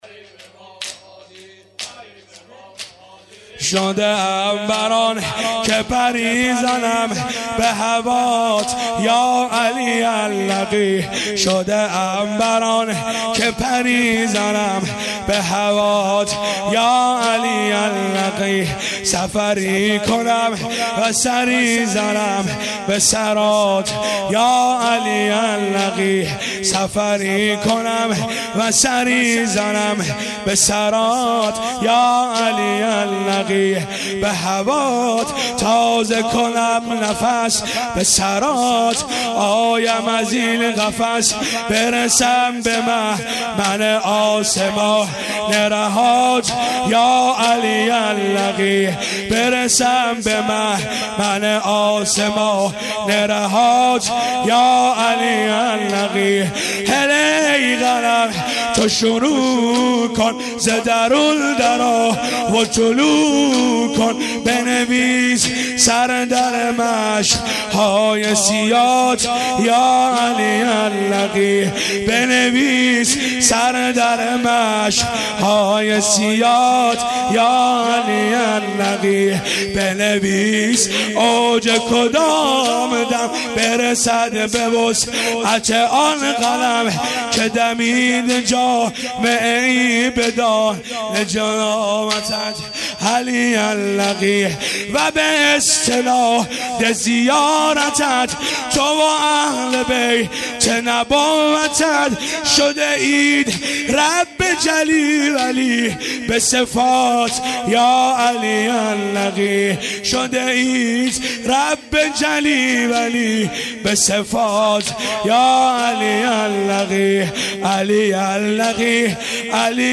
شدم بر آن که پری زنم(سینه زنی/ تک